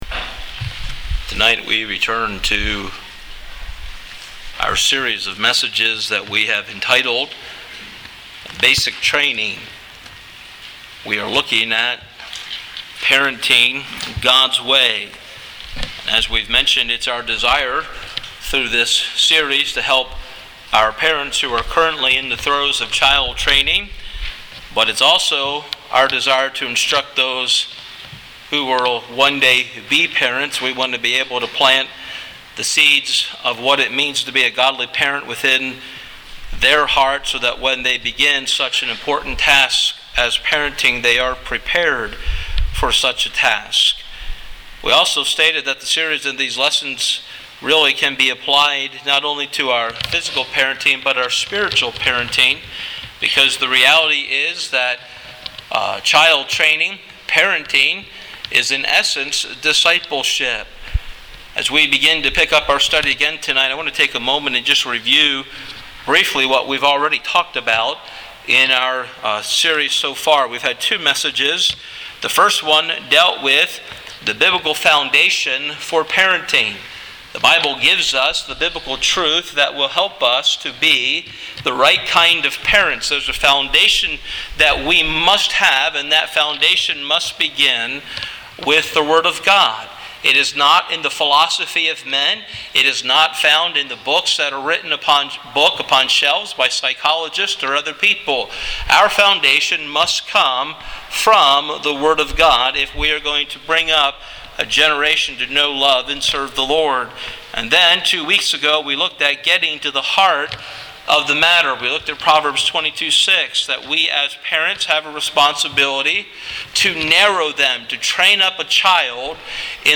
Sermon MP3